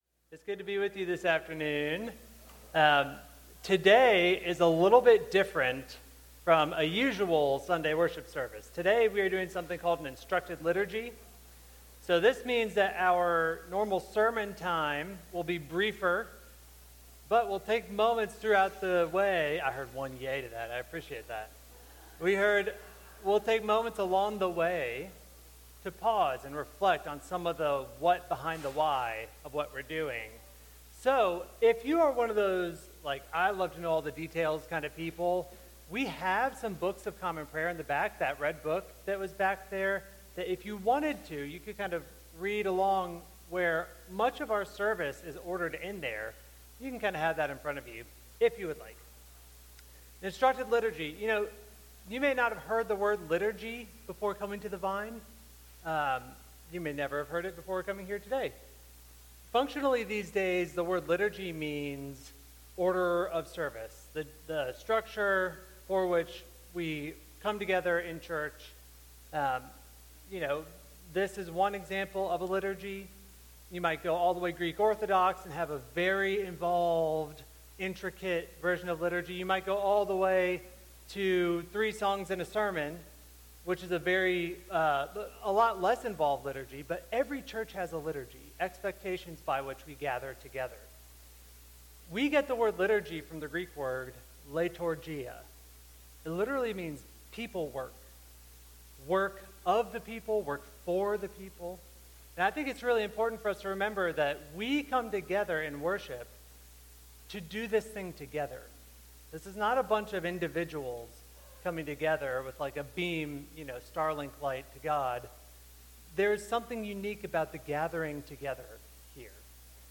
Instructed Liturgy Service